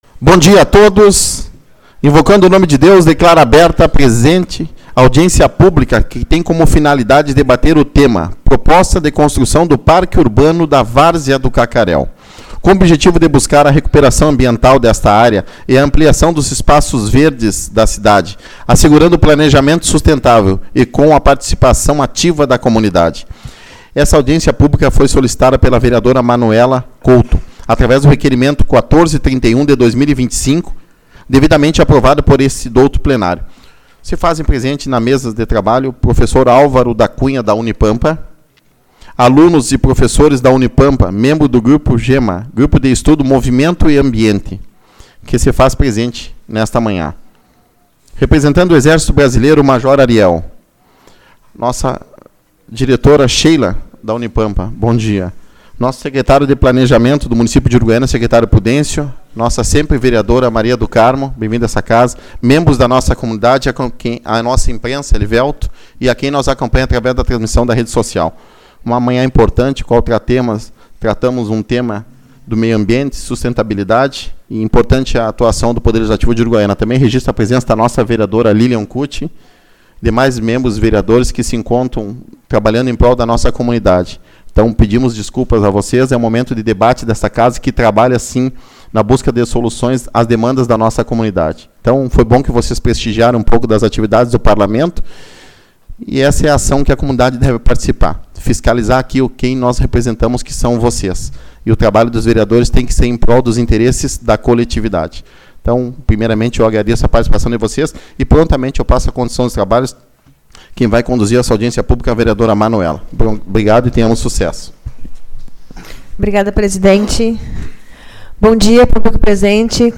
22/10 - Audiência Pública-Proj. Parque Urbano-Cacaréu